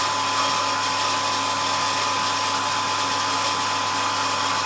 pump_loop.wav